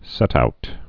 (sĕtout)